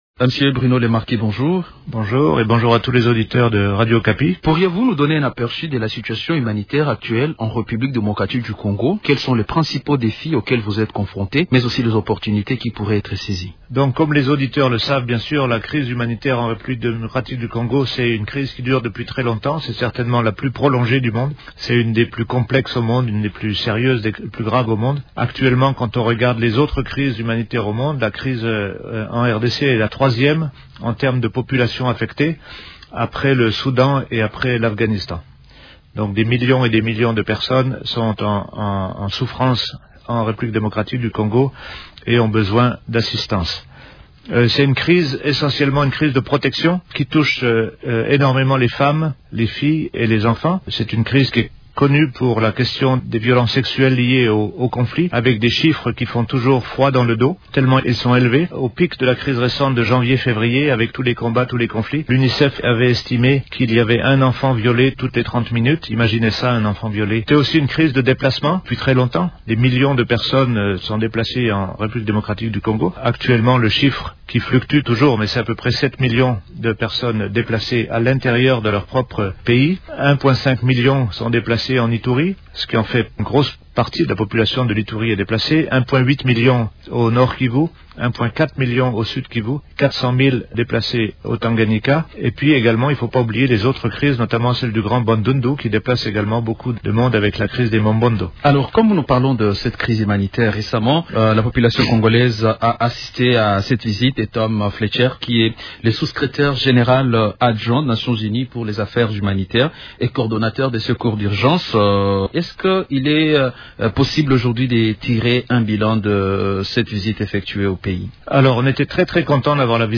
Dans un entretien exclusif accordé à Radio Okapi, il s’est dit profondément préoccupé par l’insuffisance d’aide humanitaire destinée aux populations victimes de diverses crises au pays.